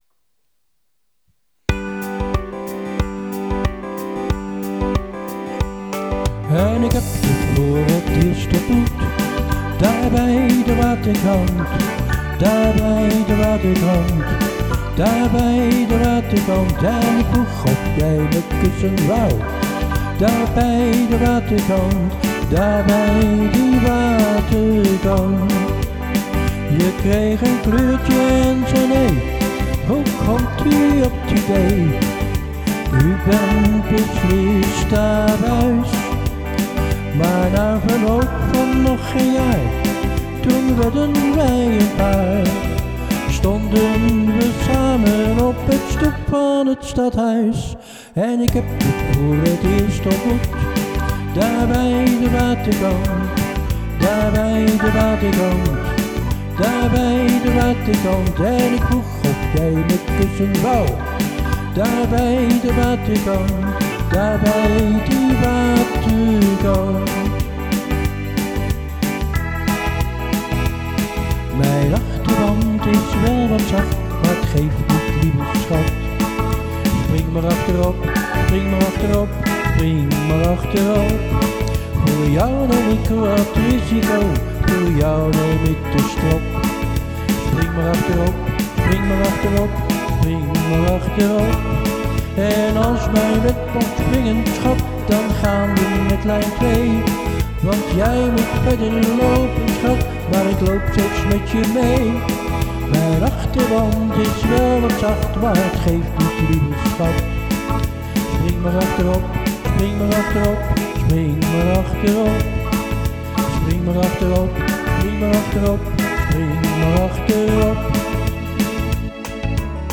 medly